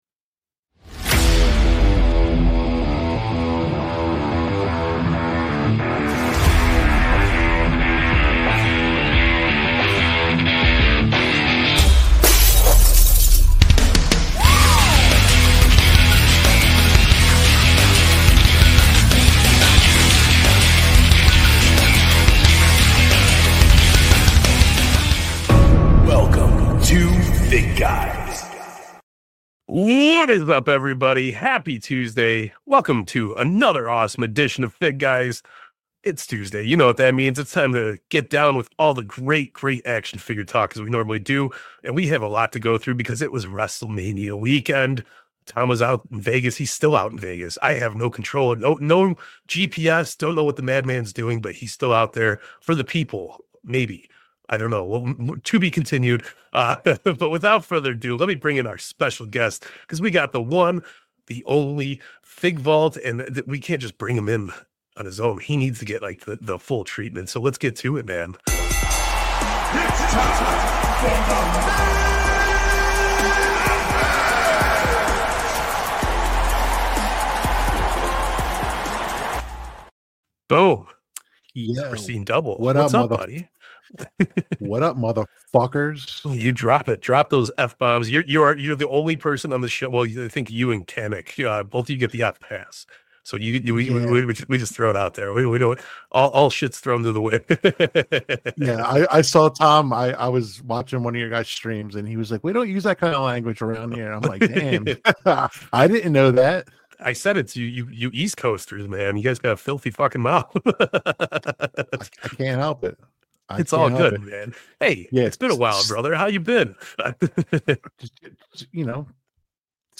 ** We would like to apologize for the audio issues in the beginning of todays show and rest assure we will work on never having that happen again **Check out the latest episode of The FigGuys with special guest The FigVault! This week the boys took a deep dive into the latest announcements from Mattel on the WWE Line featuring new Legends, Elite Series, Main Event figures!